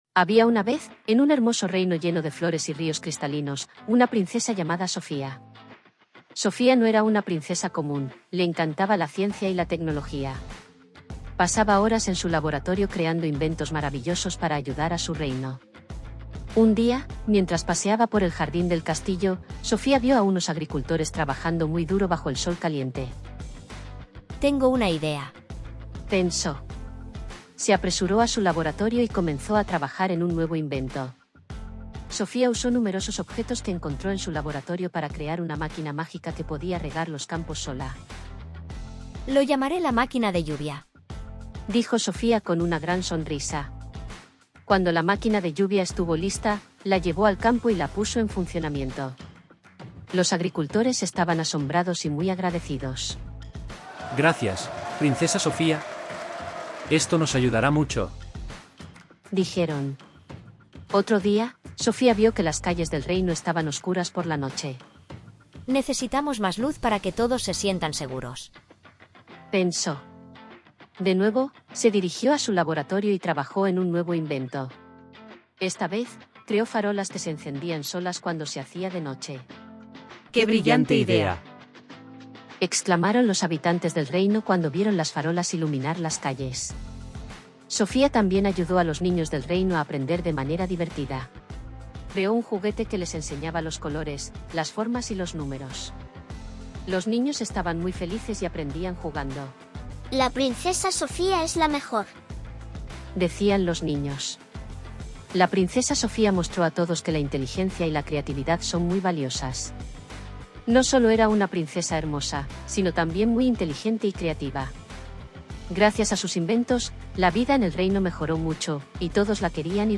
9.-Audiolibro-La-princesa-inventora-v2.mp3